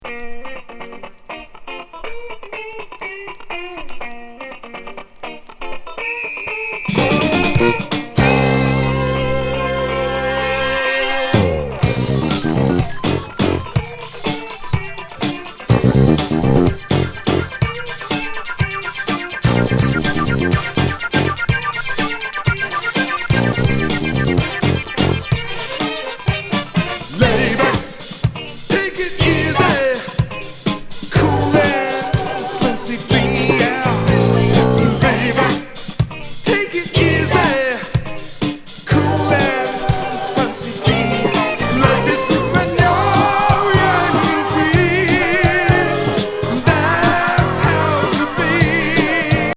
イントロ電子音からファンキー・